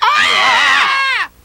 • GROUP SCREAMING.wav
GROUP_SCREAMING_lFt.wav